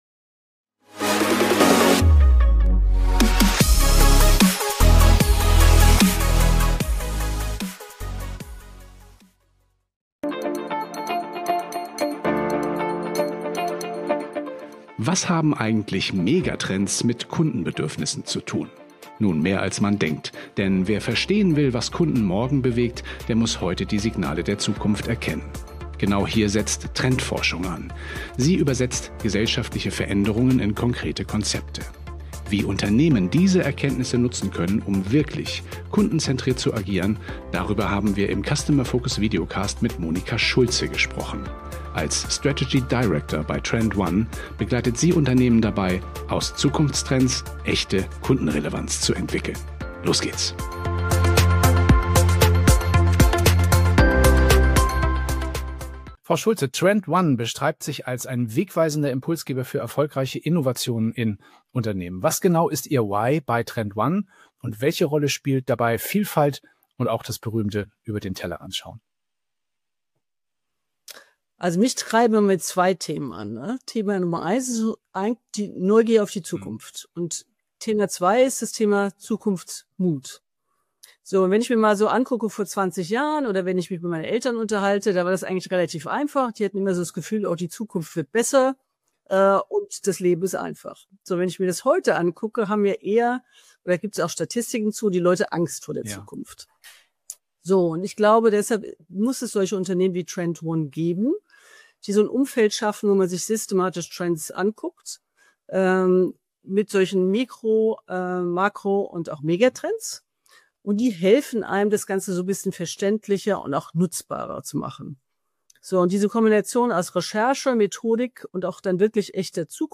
Ein inspirierendes Gespräch über Zukunftskompetenz, Innovationskultur und die Frage, wie Unternehmen den Mut finden, ihre Zukunft aktiv zu gestalten, statt auf andere zu warten.